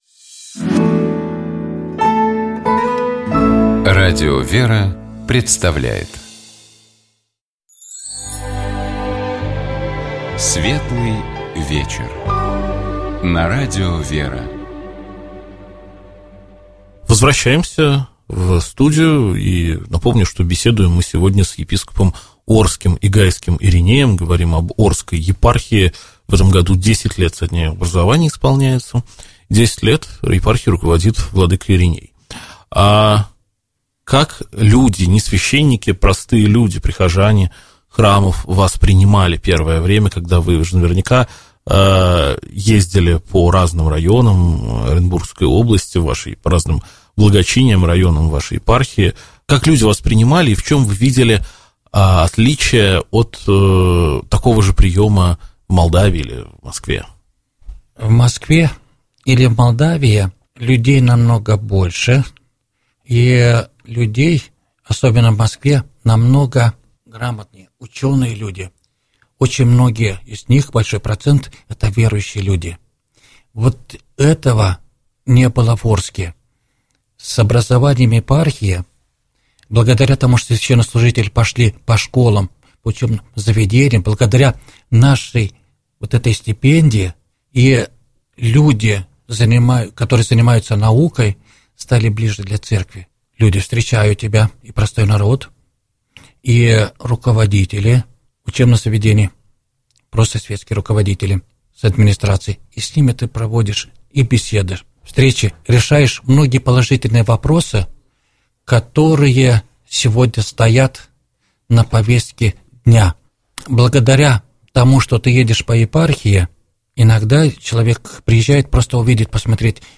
Нашим собеседником был епископ Орский и Гайский Ириней.